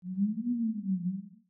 Babushka / audio / sfx / Battle / Enemies / SFX_Mavka_Voice_03.wav
SFX_Mavka_Voice_03.wav